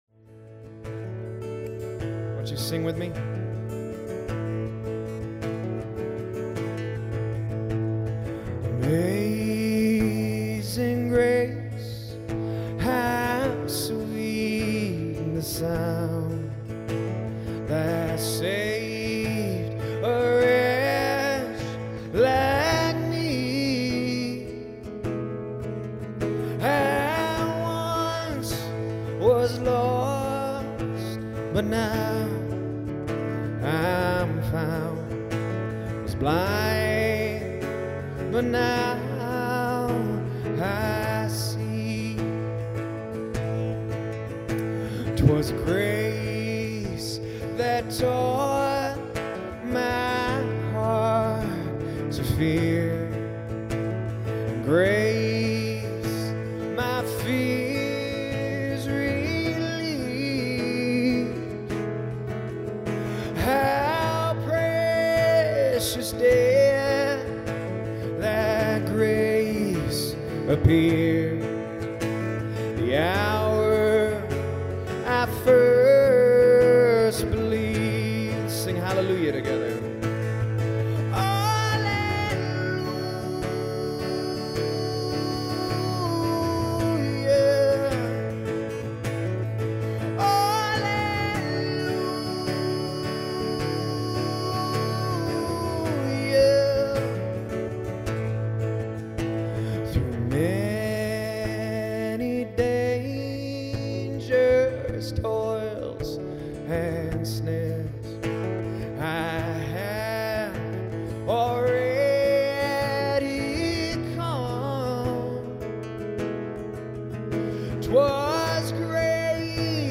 In this first message of our Easter series (Death to Life), you will experience dramatic story telling, meaningful music, and purposeful participation. All of these will help you understand what Jesus and His disciples went through on the night He was betrayed.